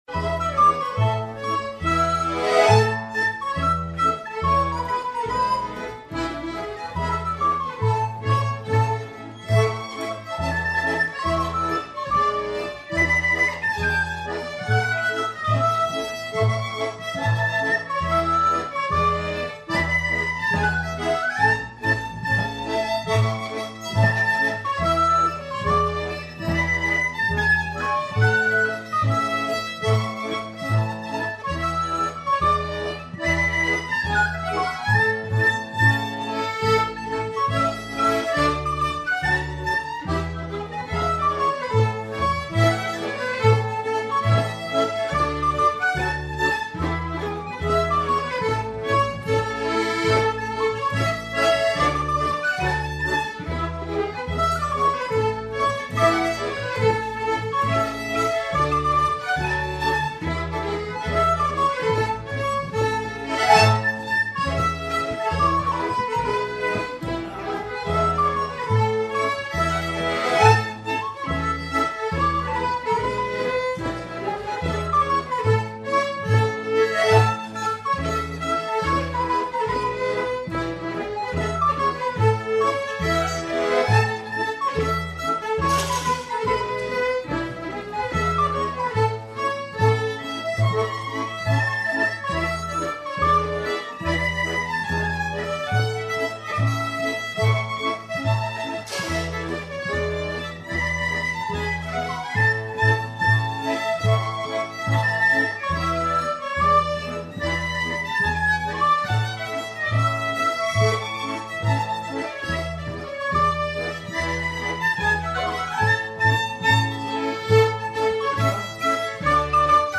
Musikken p� �lejren p� Ly�
ane-louises-schottish.mp3